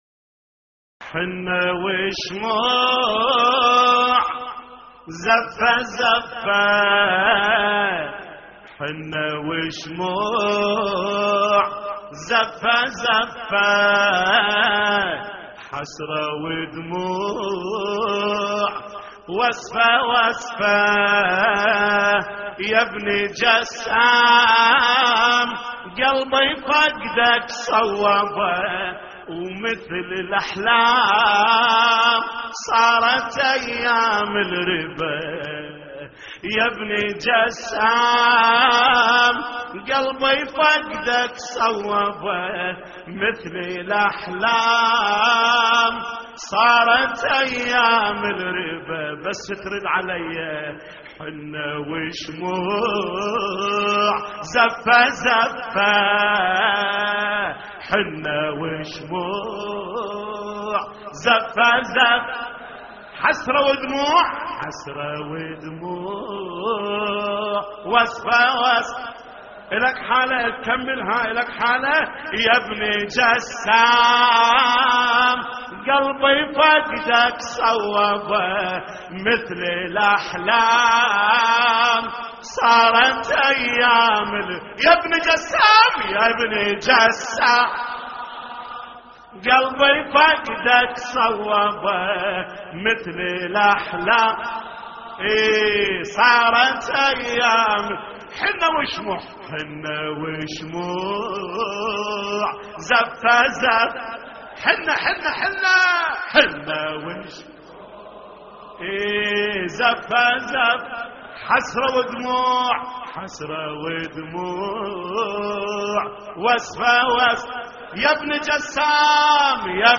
تحميل : حنّة وشموع زفّة زفّة حسرة ودموع وسفة وسفة / الرادود جليل الكربلائي / اللطميات الحسينية / موقع يا حسين